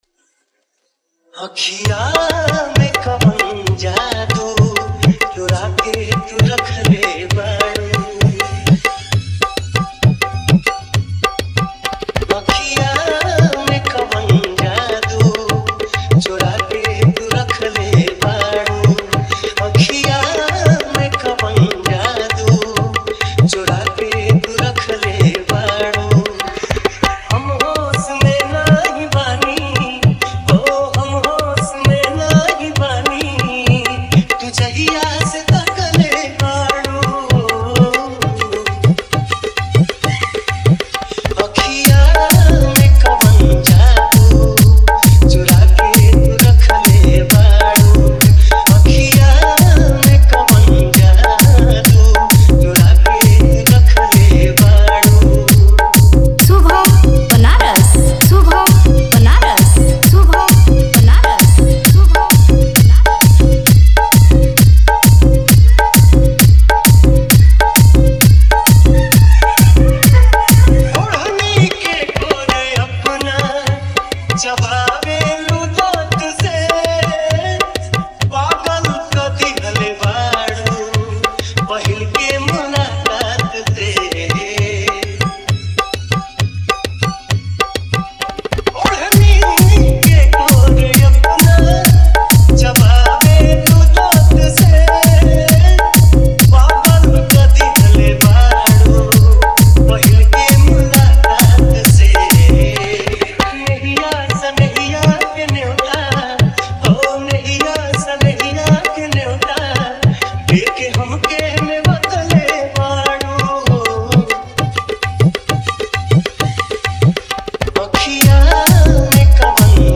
New Bhojpuri Dj Remix